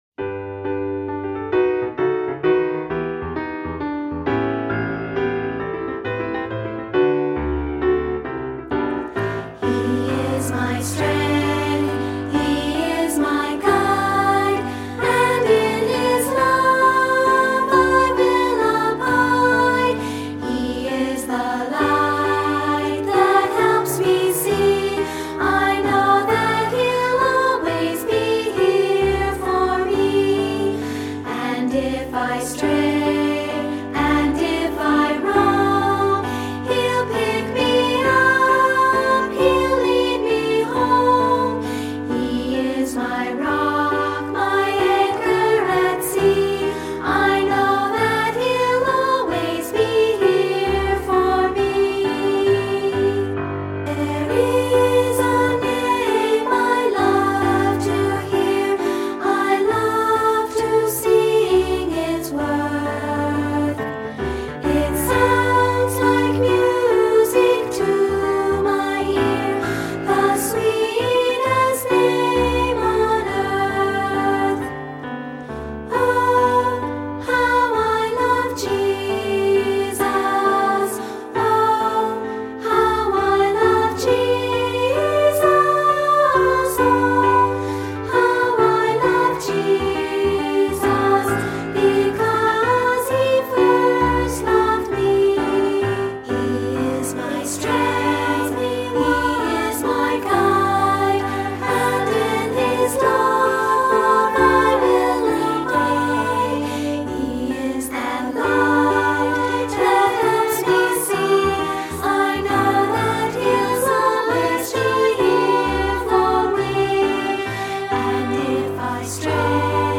Voicing: SA and Piano